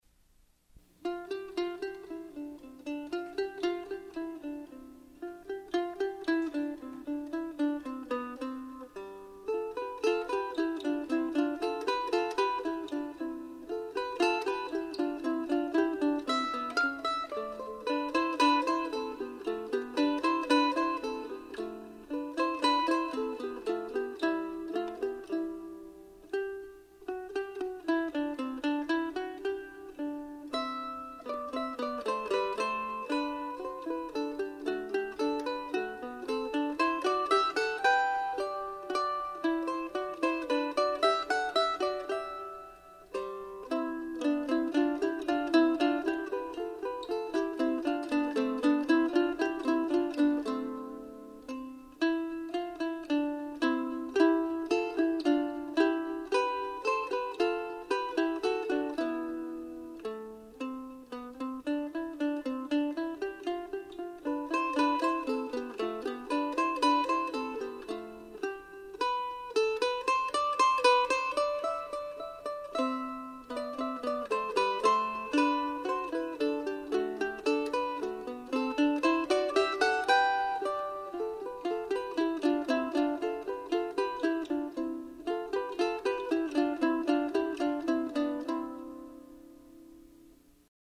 Here are two simple duets for two mandolins (or any other instruments with similar ranges) that I wrote down sometime around 1980.
The challenge in playing these short tunes is to keep them from sounding mechanical and to allow them to breathe a little.